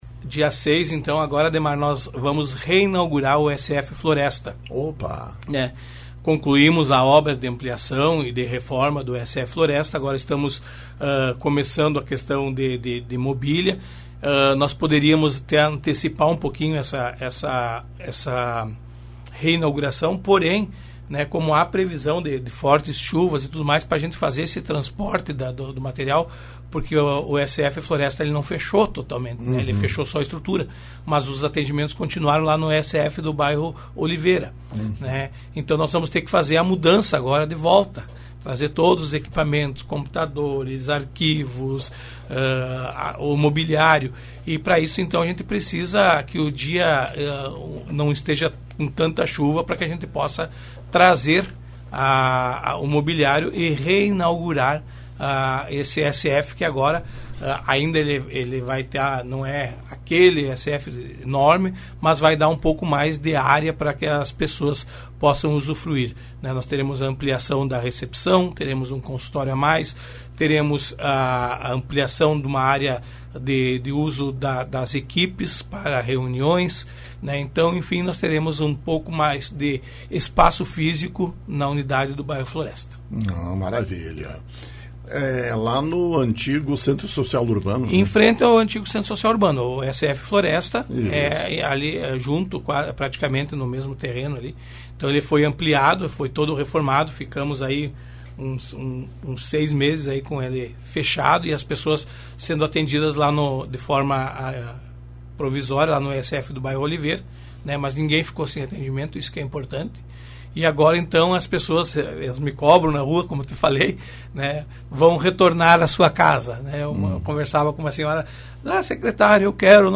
Informação transmitida pelo secretário municipal de Saúde, Eloir Morona, na manhã desta segunda-feira, em entrevista à Rádio Lagoa FM. Está marcada para o dia 6 a reinauguração da ESF do Bairro Floresta. recebeu reformas e ampliações.